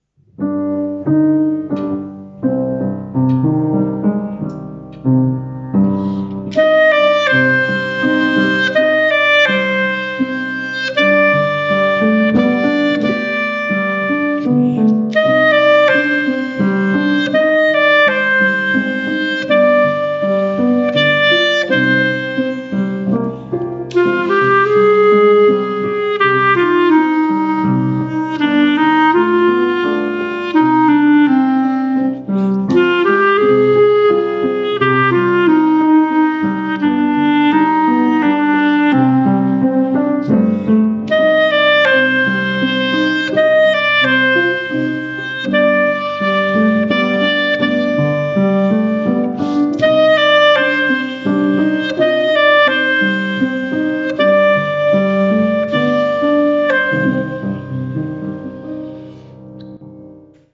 With the clarinet
Mountain-Stream-with-Solo.mp3